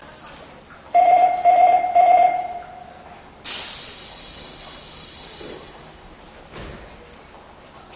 発車予告ベル（メロディ）
名古屋の地下鉄ではおなじみの、「プルルッ・プルルッ・プルルッ」という発車予告ベルです。
（東山公園駅で収録）
5050形や2000形と似ていますが少し違った音色です。